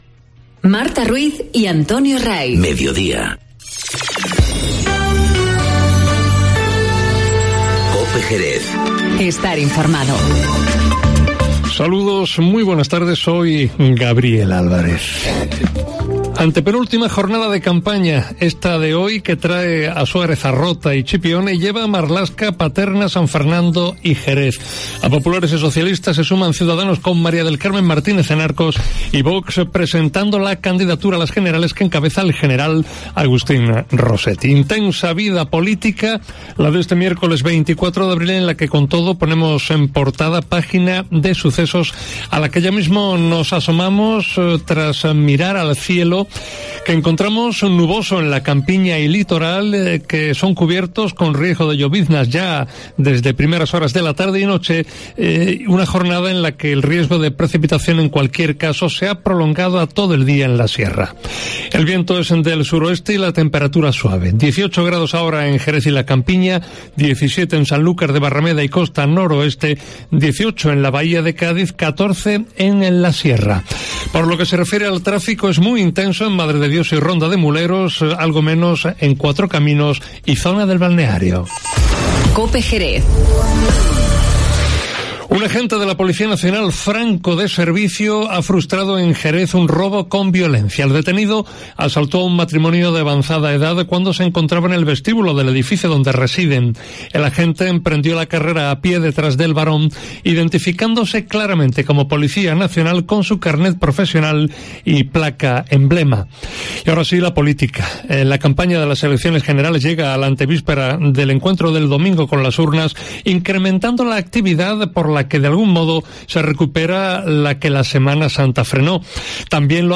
Informativo Mediodía COPE Jerez (24/04/19)